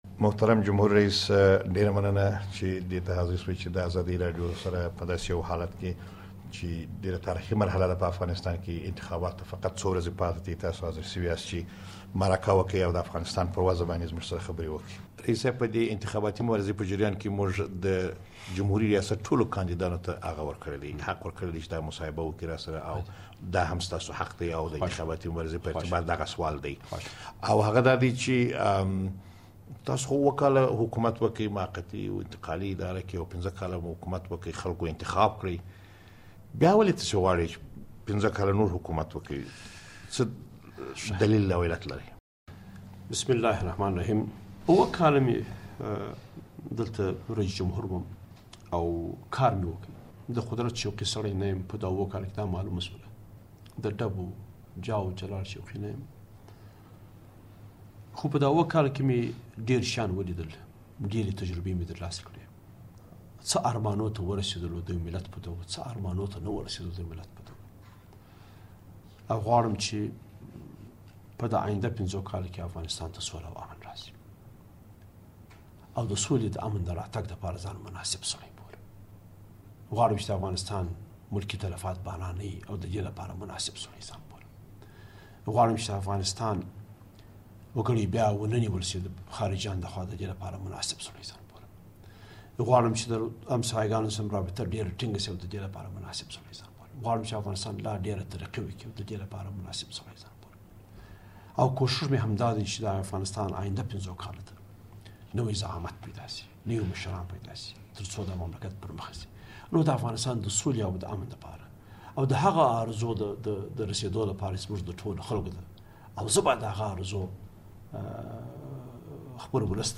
له ولسمشر حامد کرزي سره ځانګړې مرکه واورﺉ